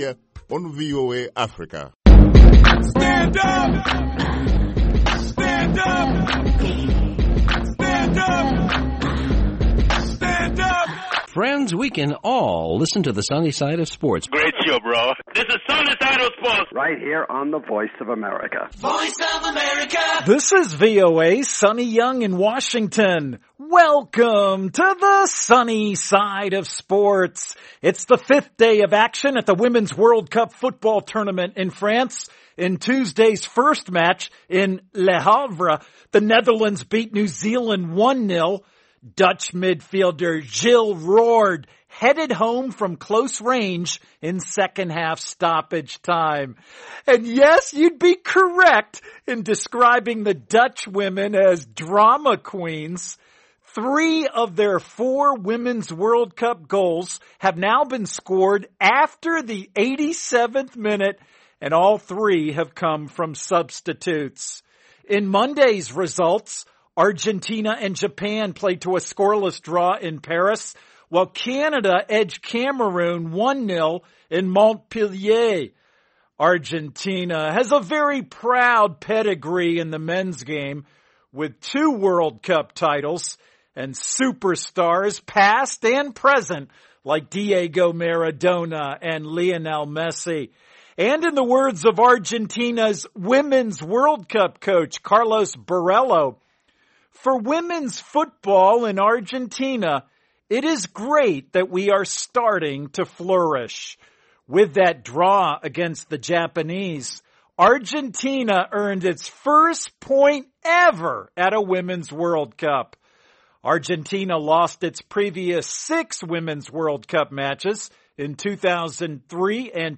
If you want to know what’s trending in news, health, sports and lifestyle, then tune in to Africa 54. Airing Monday through Friday, this 30-minute program takes a closer look at the stories Africans are talking about, with reports from VOA correspondents, and interviews with top experts and...